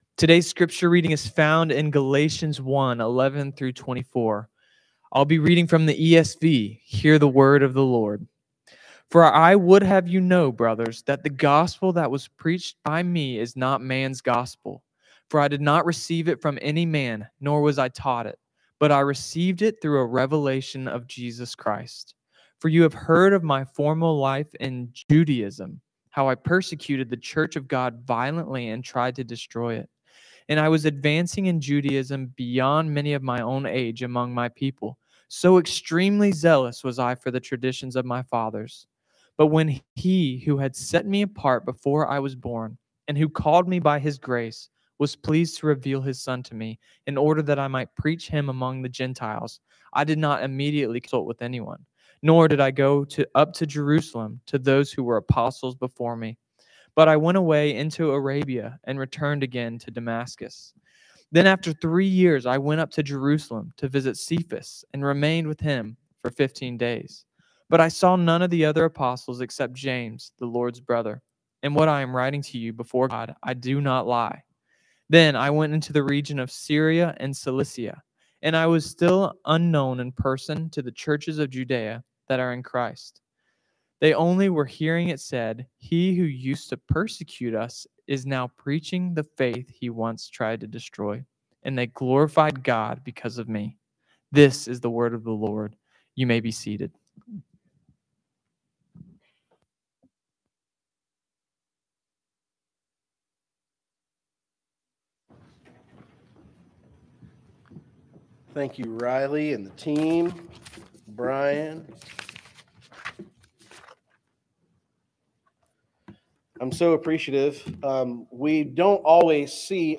Sermons | Waleska First Baptist Church